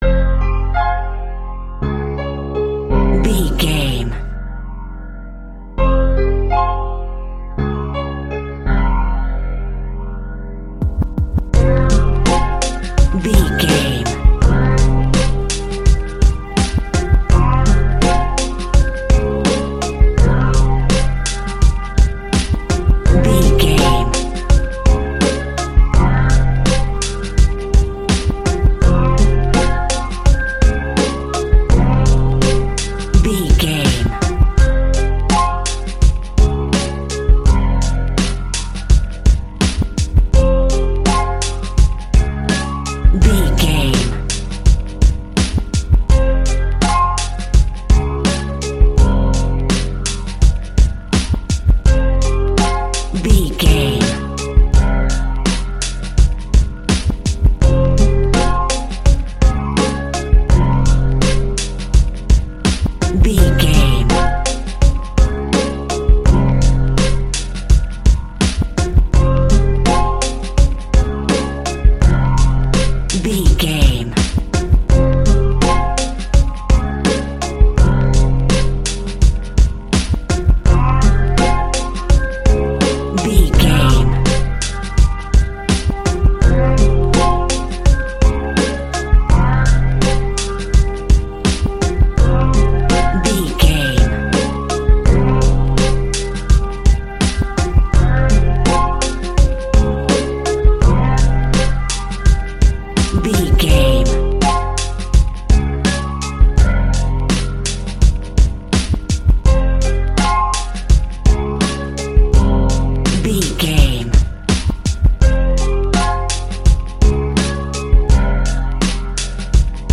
Aeolian/Minor
hip hop
chilled
laid back
groove
hip hop drums
hip hop synths
piano
hip hop pads